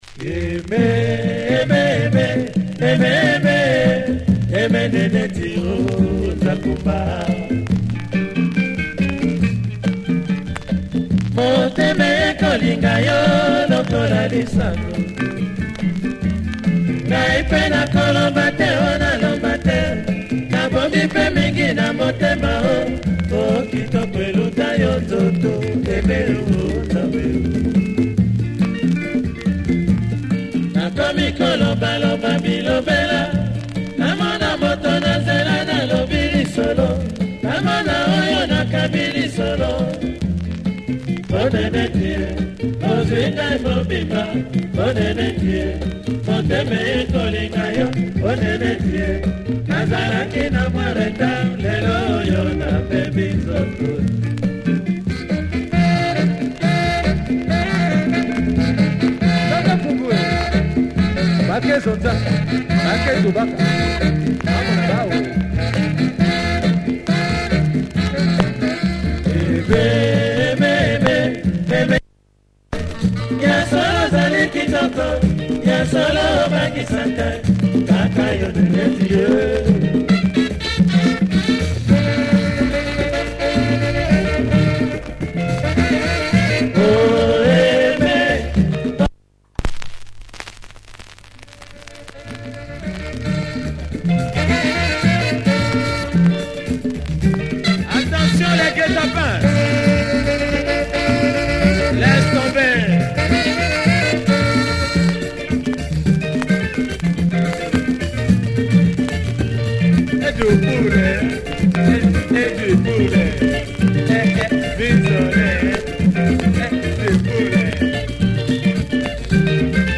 Quality lingala
Good groove and backbone!